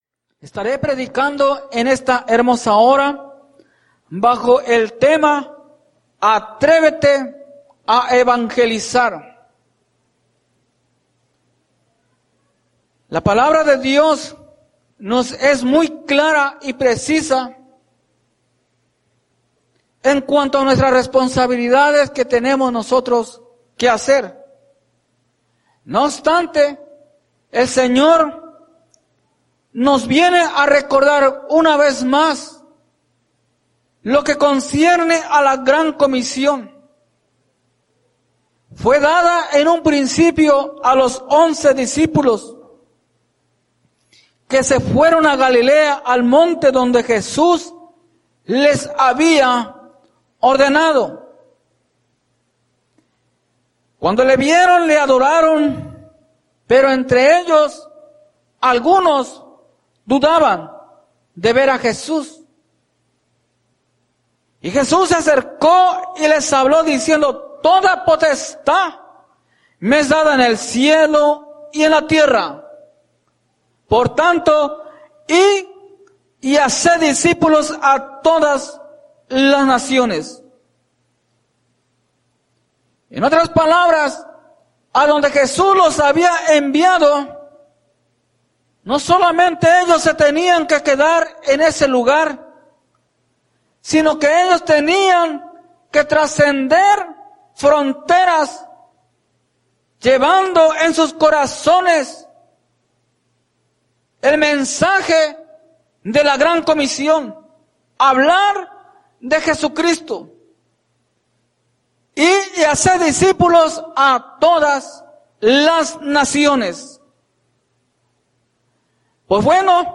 Atrévete a Evangelizar Predica
Norristown,PA